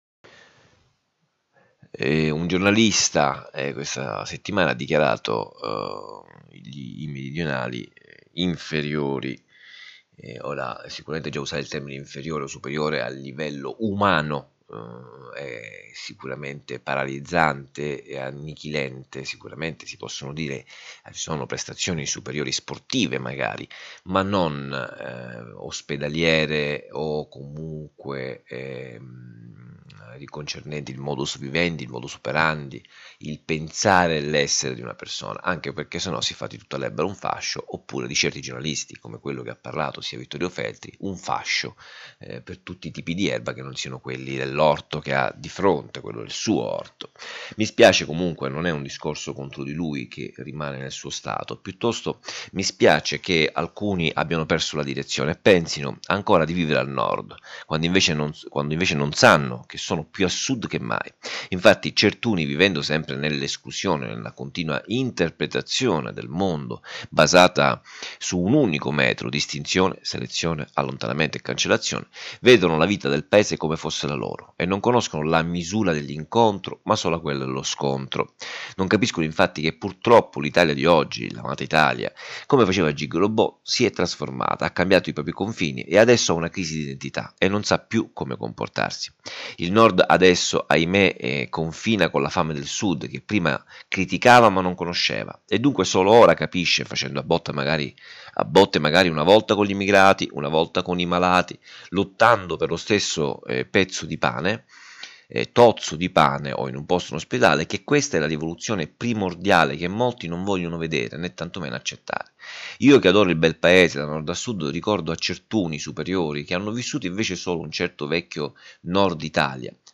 3 MINUTI D’AUDIO Riproducono la riflessione dell’articolo a voce alta, perché abbiano accesso all’ascolto i ciechi,  chi lavorando non ha tempo o chi preferisce ascoltare, e quelli tra noi che pur avendo la vista sono diventati i veri Non Vedenti.